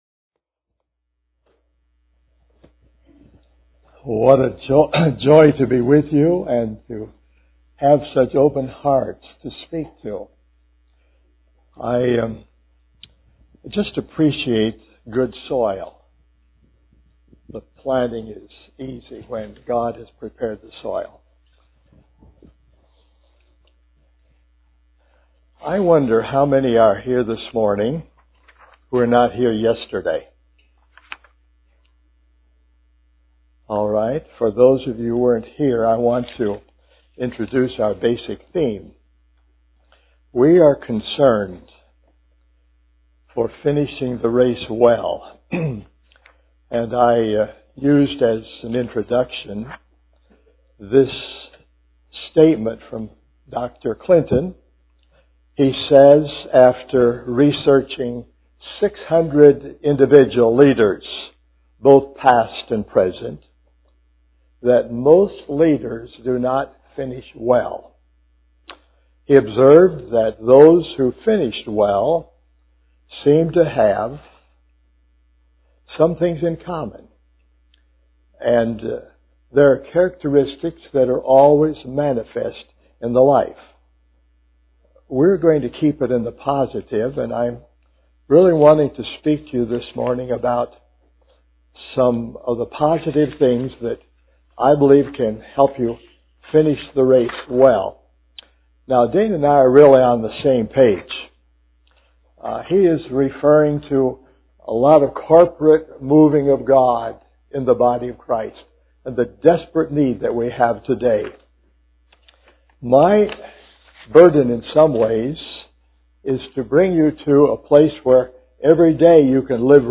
A collection of Christ focused messages published by the Christian Testimony Ministry in Richmond, VA.
2008 Western Christian Conference